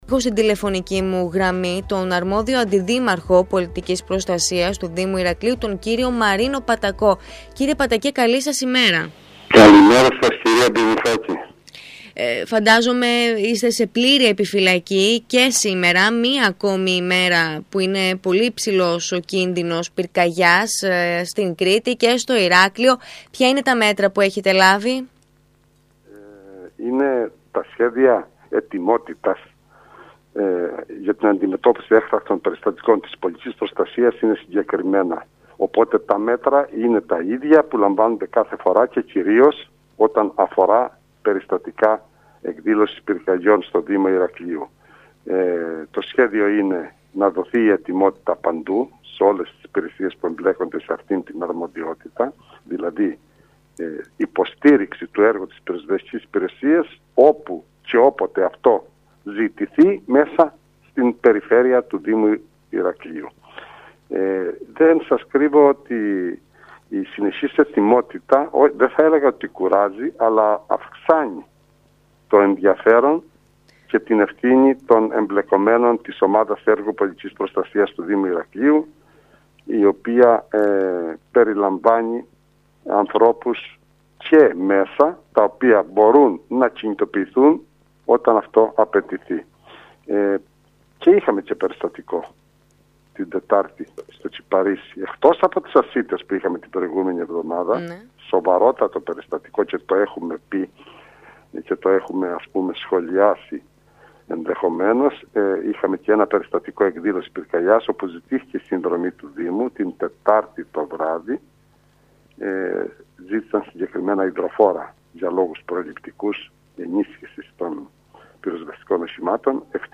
Όπως δήλωσε στον ΣΚΑΪ Κρήτης 92,1